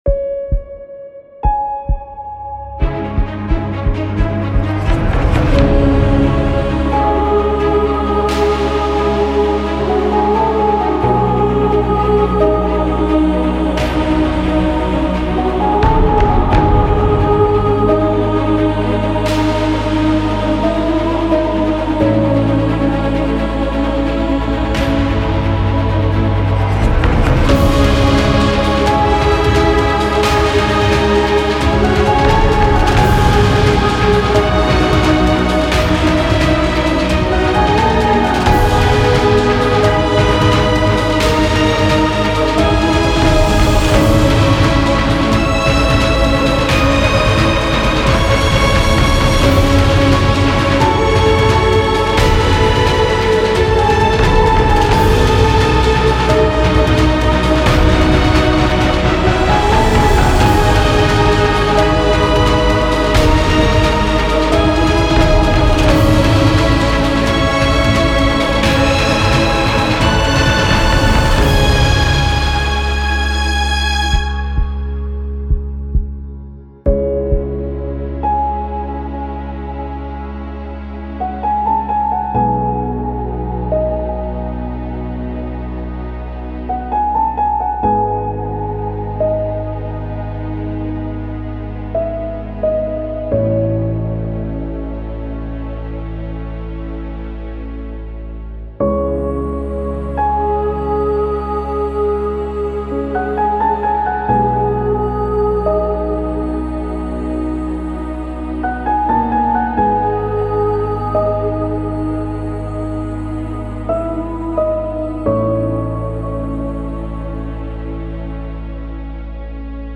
موسیقی بی کلام حماسی
موسیقی بی کلام ارکسترال موسیقی بی کلام با شکوه